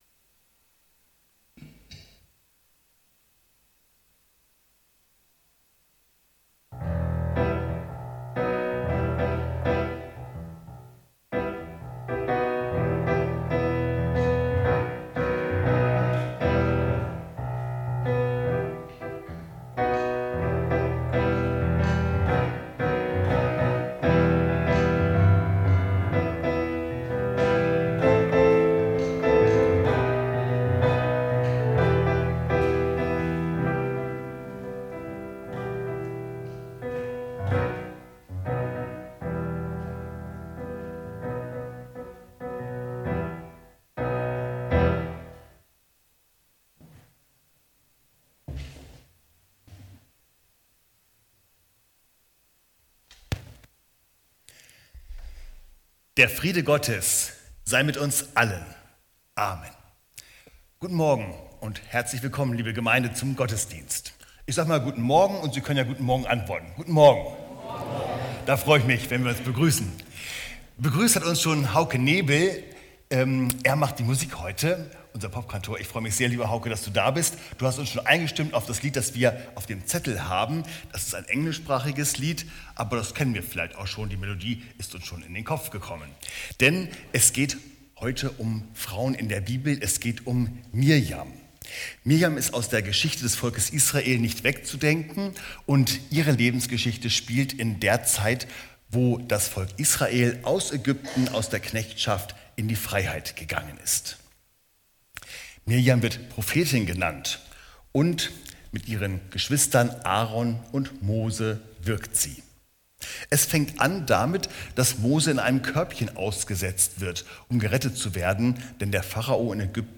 Gottesdienst - 20.07.2025 ~ Peter und Paul Gottesdienst-Podcast Podcast